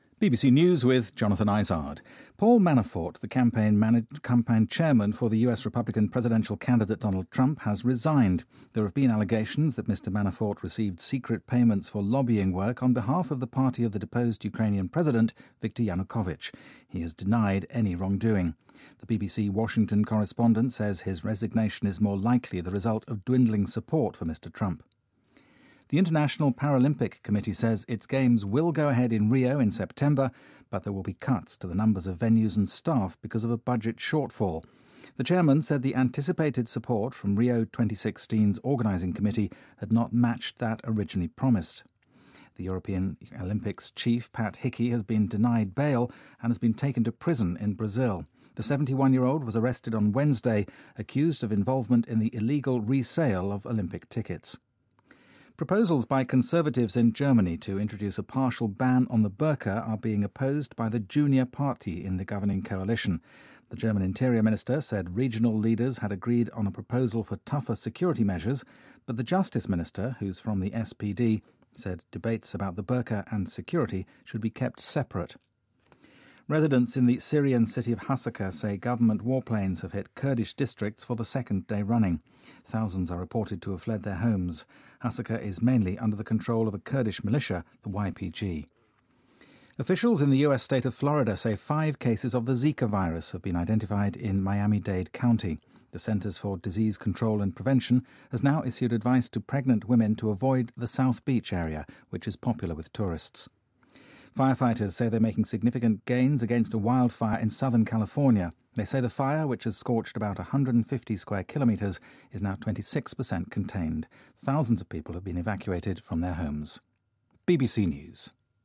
BBC news,欧洲奥委会主席帕特里克·希基涉嫌转售门票被捕
日期:2016-08-22来源:BBC新闻听力 编辑:给力英语BBC频道